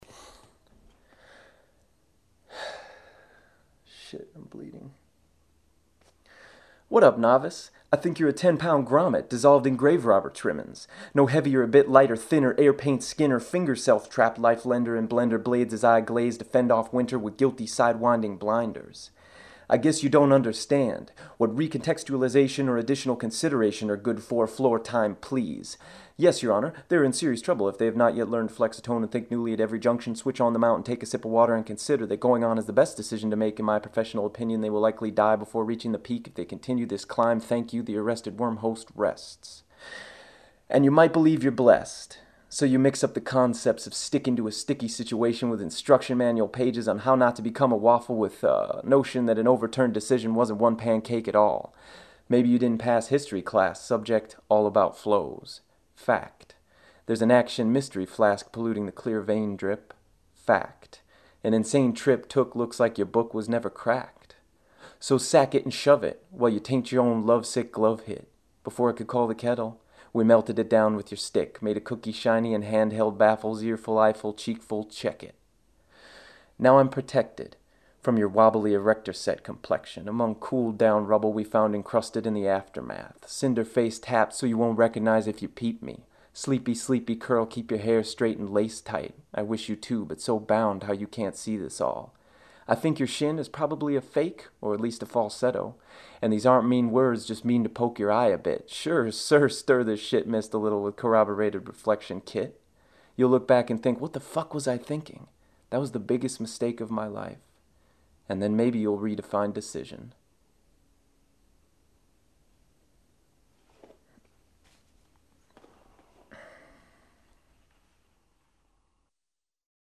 recorded in the forest  february - april 2006
spoken word